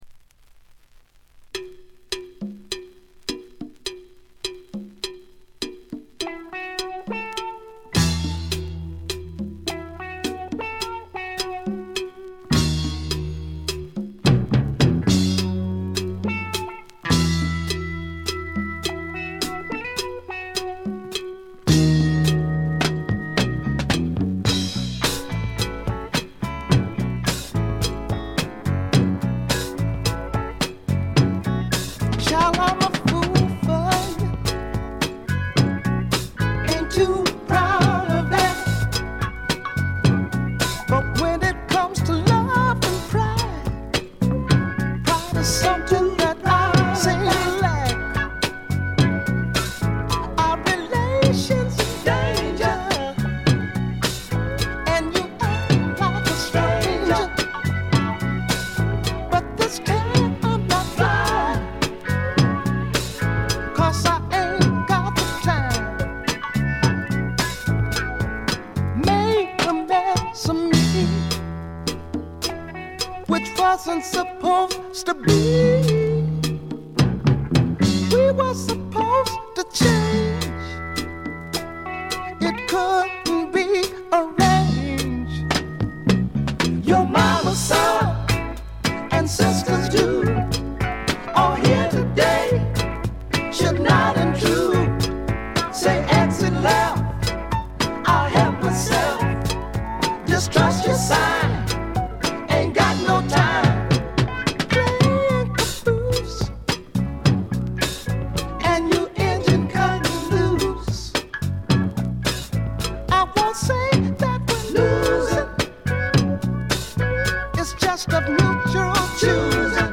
静音部での微細なバックグラウンドノイズ程度。
試聴曲は現品からの取り込み音源です。